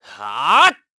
Siegfried-Vox_Attack3_kr.wav